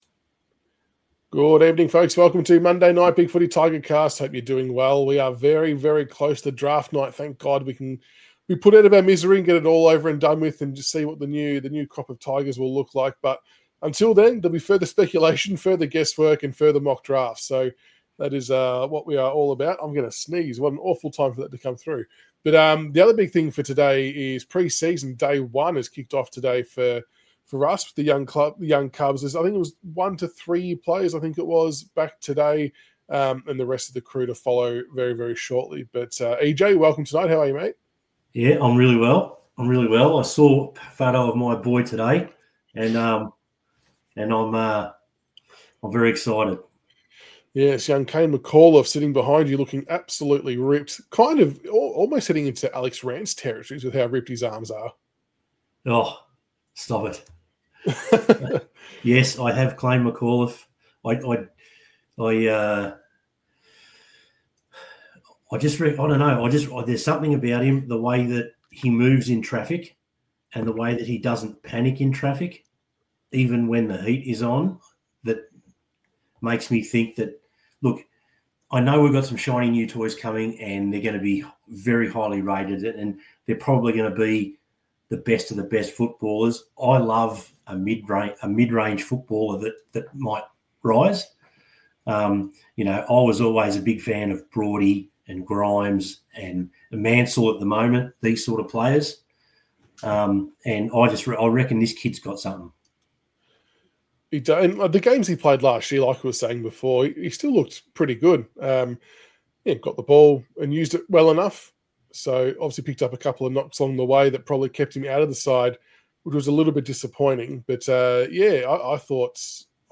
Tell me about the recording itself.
PreSeasonDay1pluswereviewsomeMockdraftsLiveShow.mp3